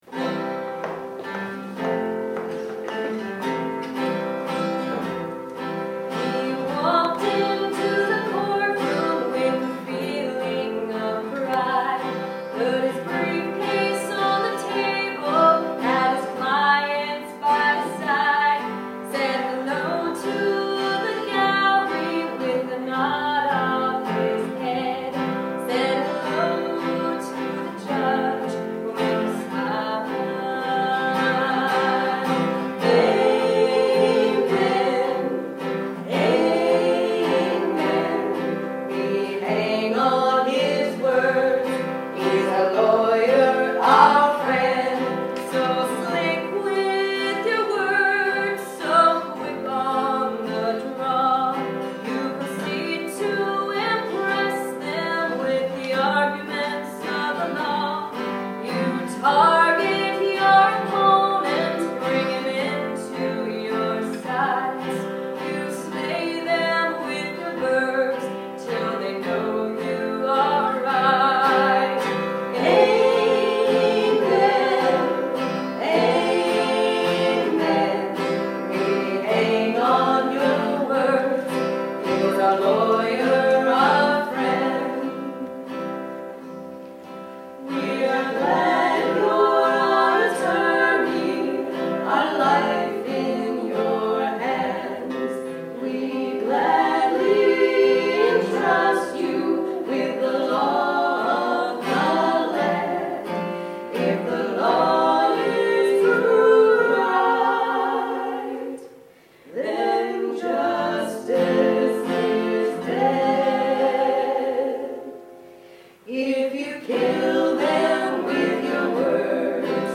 Evy, Cora, and Helen sing: